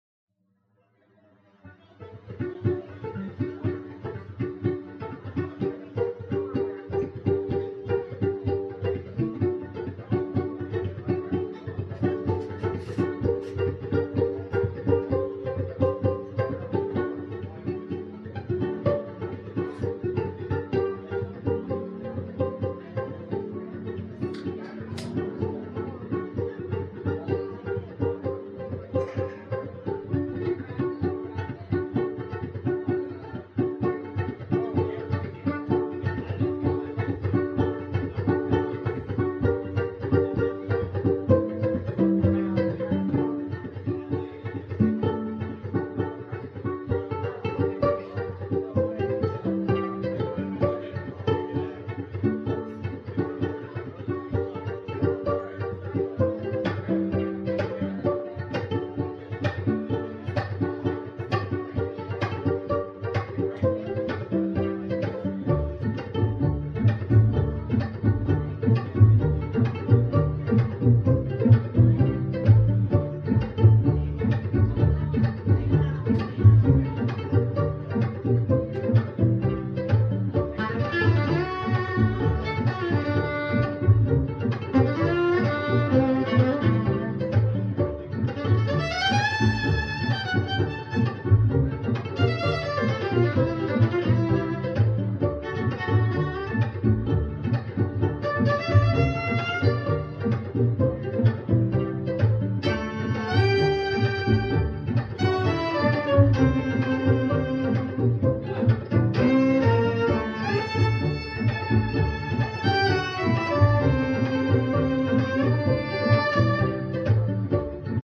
Orchestrational Build Up